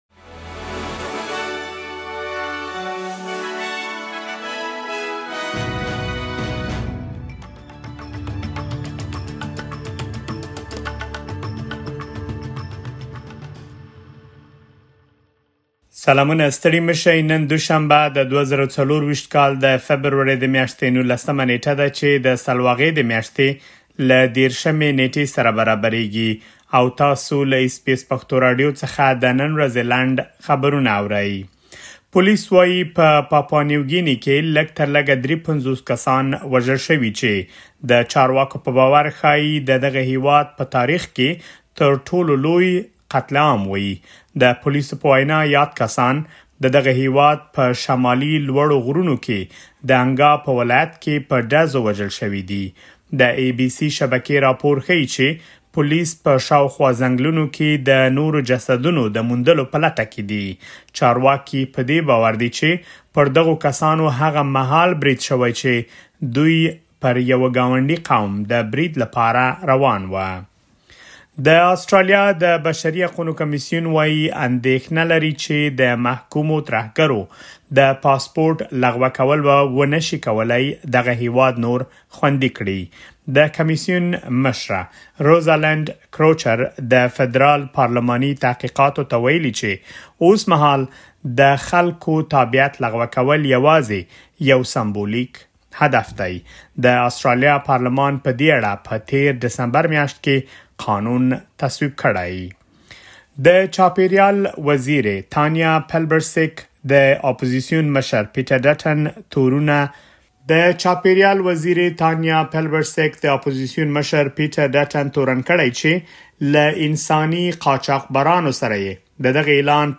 د اس بي اس پښتو راډیو د نن ورځې لنډ خبرونه |۱۹ فبروري ۲۰۲۴